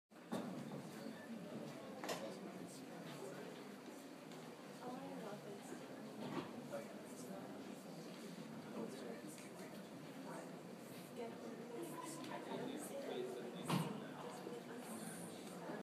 Field Recording 3
Location: NAB, backstage Sounds Heard: Crowd of audience, footsteps, chairs opening
Sounds Heard: Crowd of audience, footsteps, chairs opening
Audience-Recording.mp3